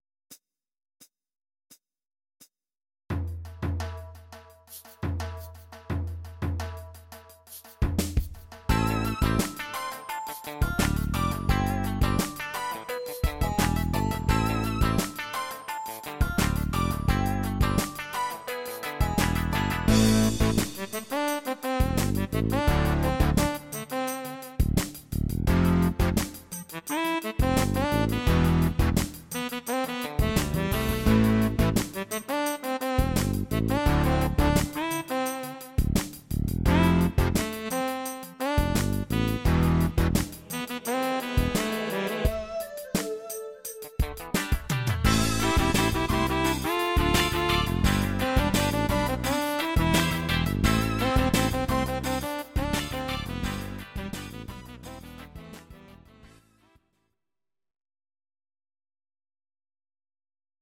Audio Recordings based on Midi-files
Pop, Musical/Film/TV, 2000s